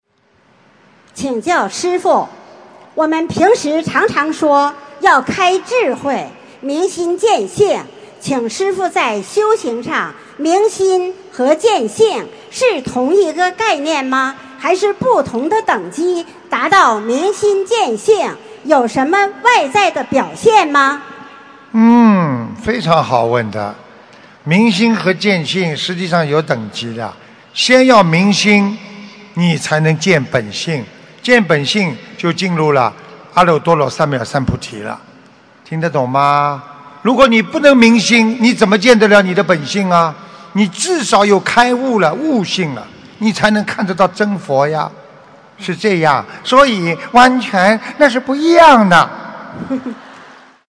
关于“明心”和“见性”的关系┃弟子提问 师父回答 - 2017 - 心如菩提 - Powered by Discuz!